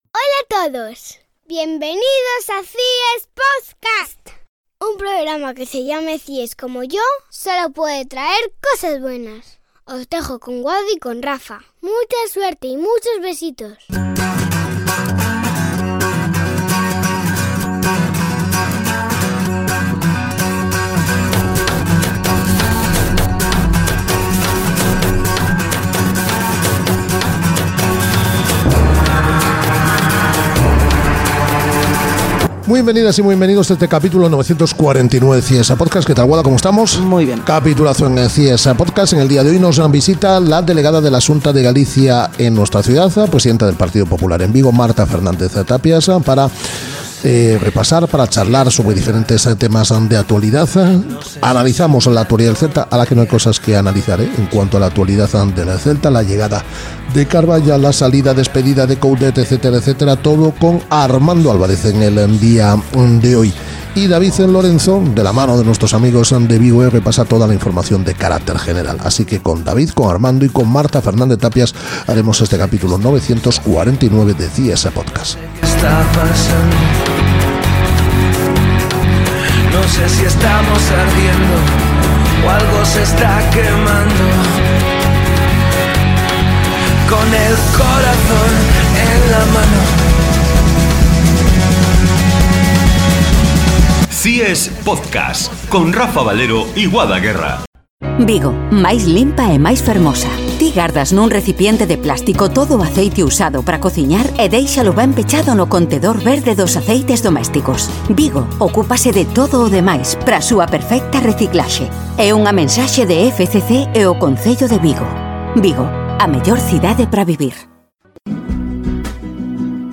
Entrevista con la delegada de la Xunta de Galicia en Vigo, Marta Fernández-Tapias.